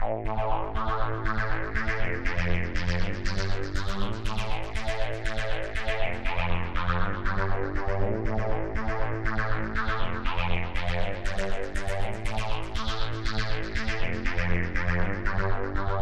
Feder Lordly Percussion Loop
标签： 120 bpm Dance Loops Percussion Loops 2.70 MB wav Key : Unknown
声道立体声